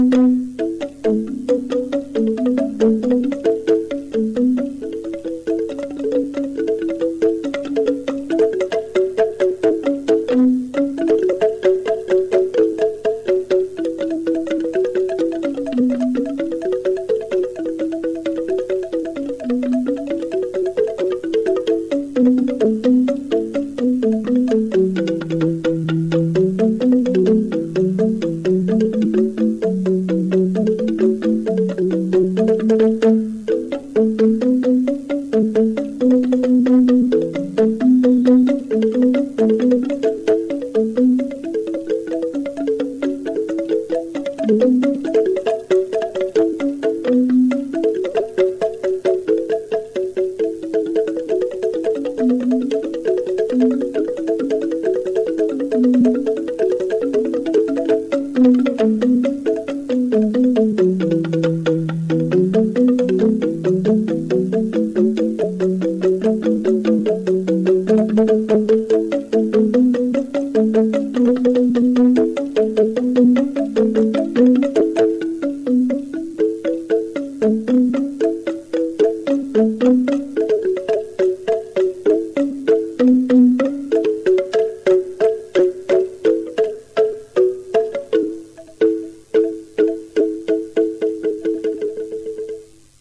竹製楽器“リンディック”の音源！素朴で優雅な響きには絶妙な趣があり◎！